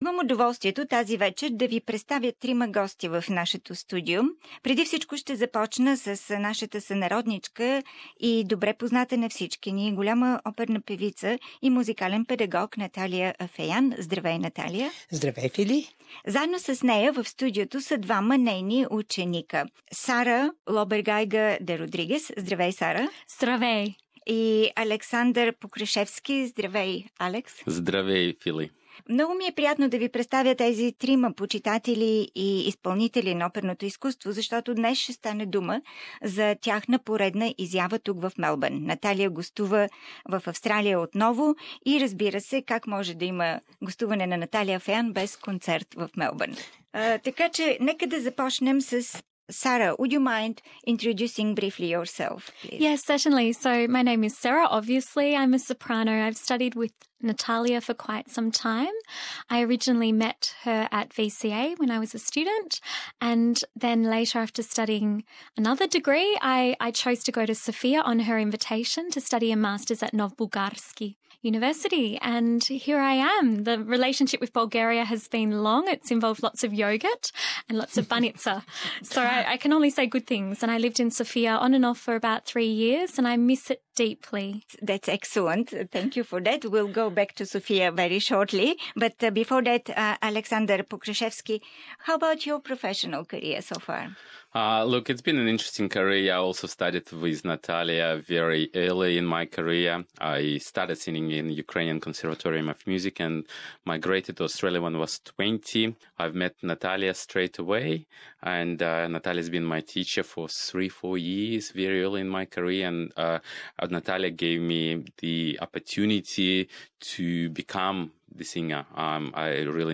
whose real dreams they openly share with us in a candid interview exclusively for the Bulgarian program on SBS radio.
perfect, bright and sweet soprano voice
the charismatic deep Ukrainian bass